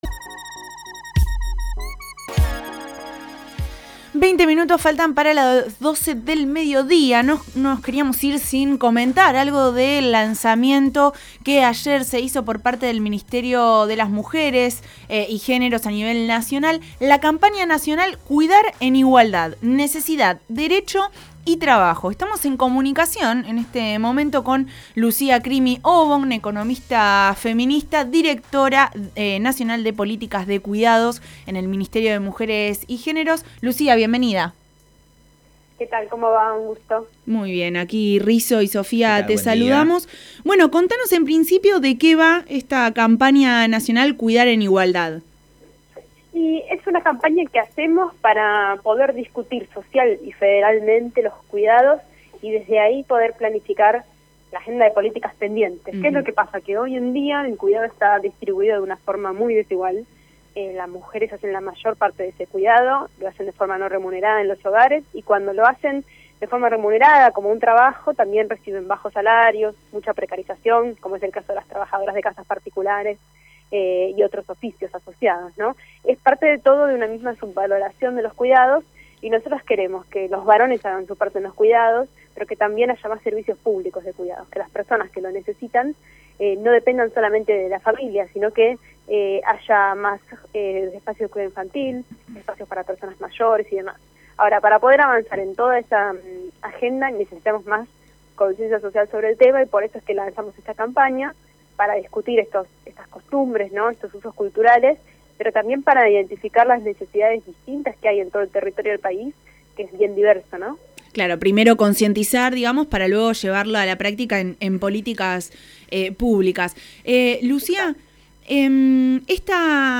En comunicación con Codo a Codo, Lucia Crimi Obon, Directora Nacional de Políticas de Cuidado en el Ministerio de Mujeres, Géneros y Diversidades, habló de la situación actual de los cuidados en cuarentena y el lanzamiento de esta política publica para concientizar y transformar estas realidades.